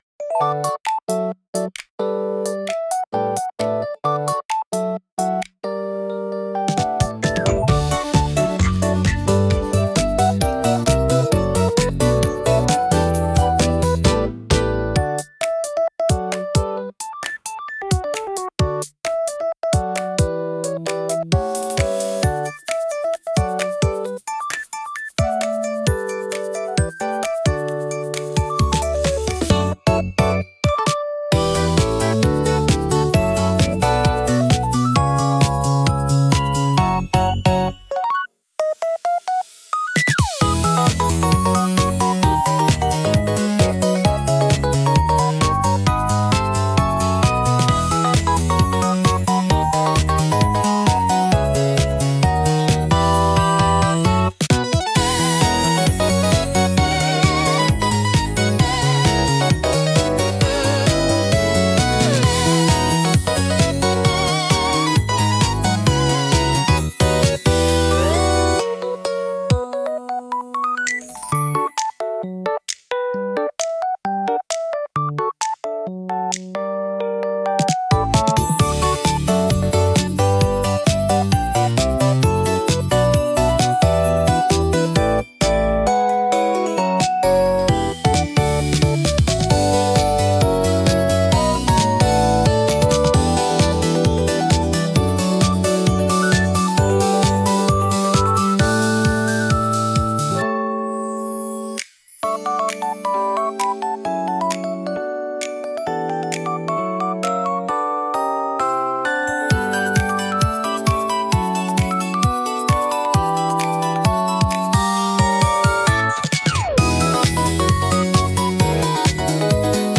ゆめかわいいフリーBGM🧸🎧🫧
ゆめかわポップBGM